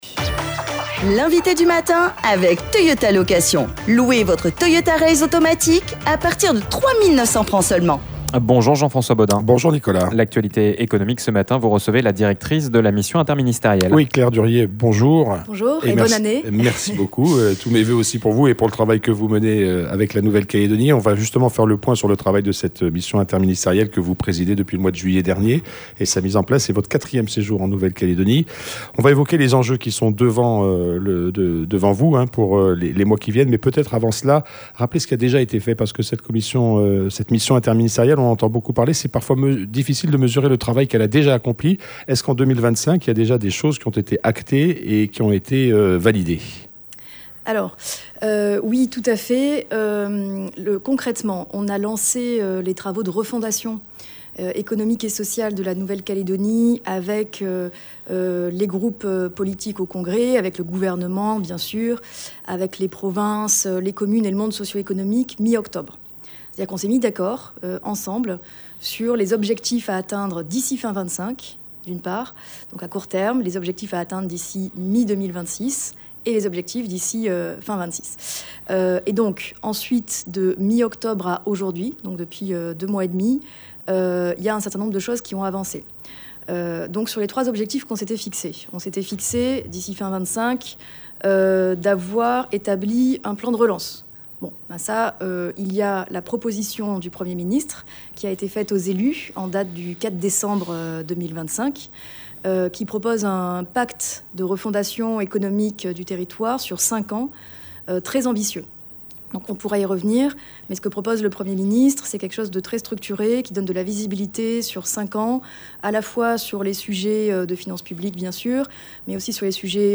L'INVITE DU MATIN : Claire Durrieu
Notre invitée est Claire Durrieu, qui dirige la mission interministérielle de reconstruction.